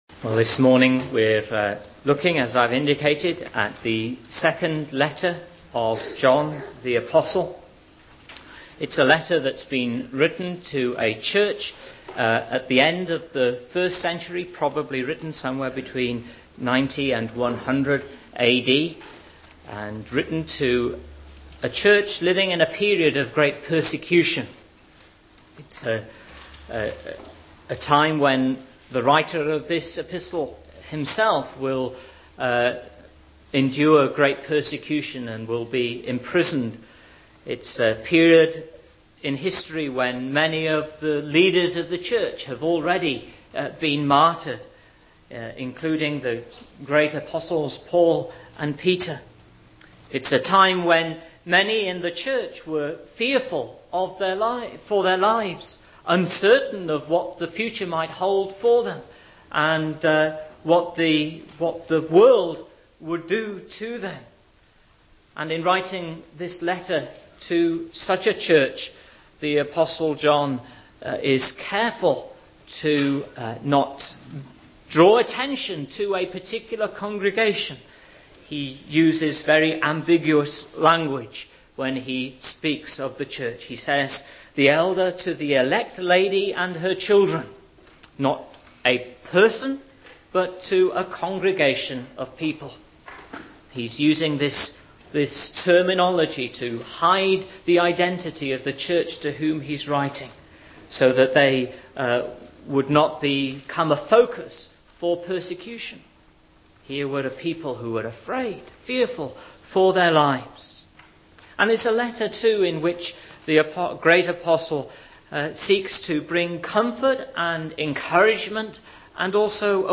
MP3 SERMON ARCHIVE